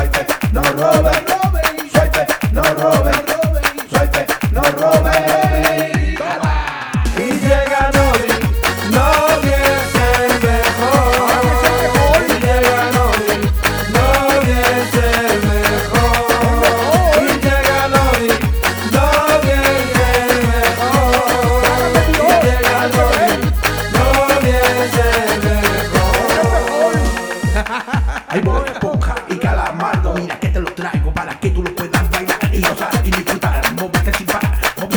Жанр: Латиноамериканская музыка